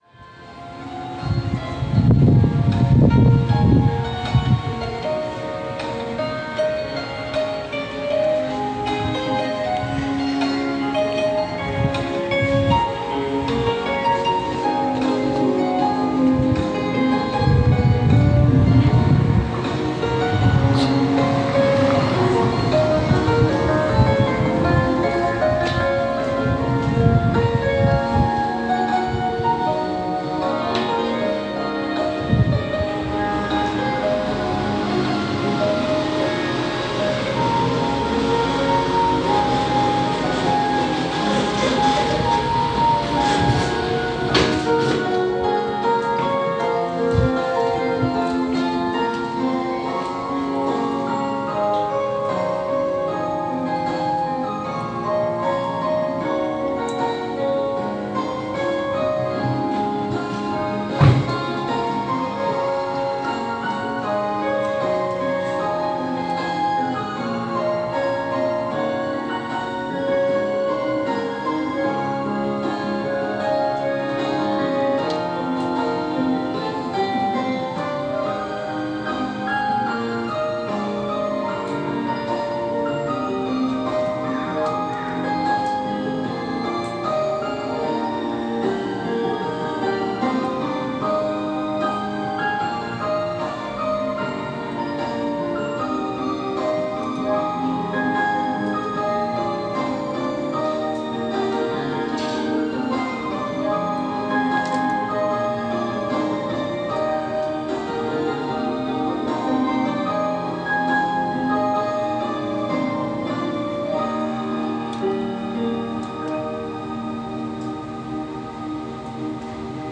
味の大王まで行く通りのシャッター商店街に爆音で流れてた音楽（曲名不明）
なんかエンディングテーマみたいな感じですね、、
かつて栄えた鉄鋼の町・室蘭のエンディングを表現してるかのよう…